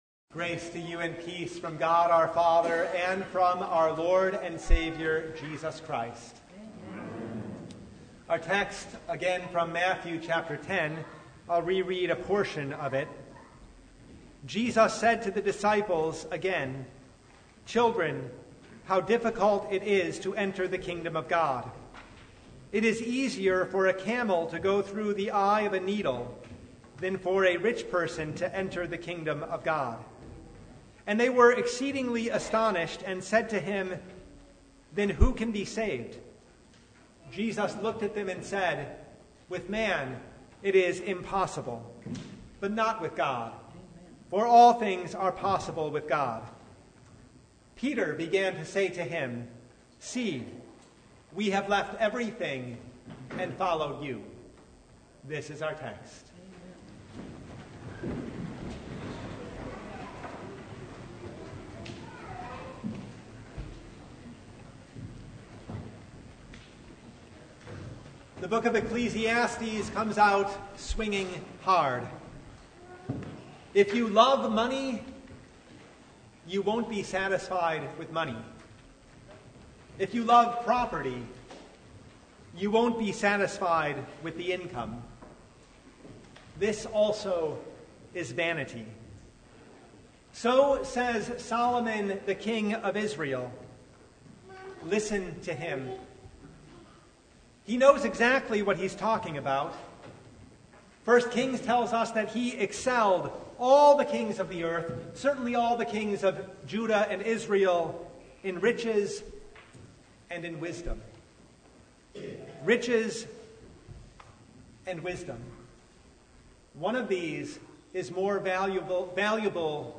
Mark 10:23–31 Service Type: Sunday The love of wealth is a barrier to entering the kingdom of God.